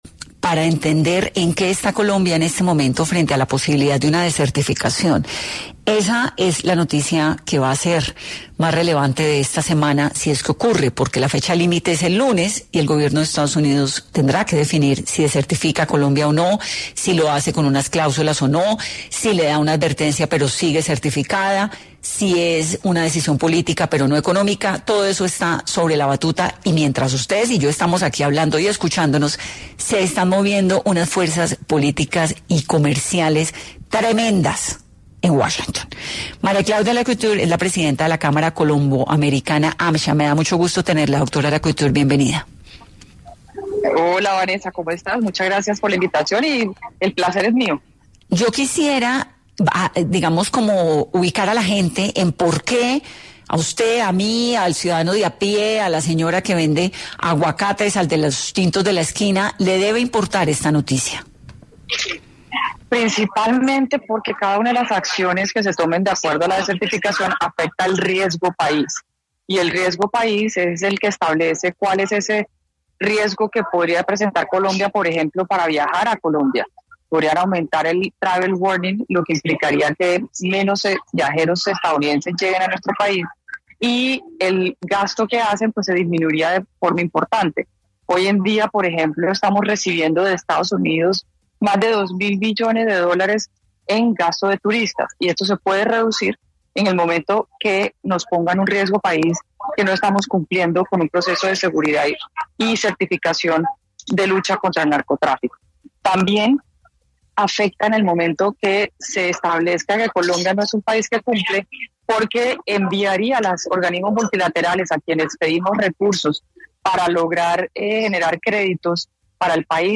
En 10 AM de Caracol Radio, estuvo María Claudia Lacouture, presidenta de la Cámara de Comercio Colombo Americana, quien habló sobre las consecuencias que traería la desertificación a Colombia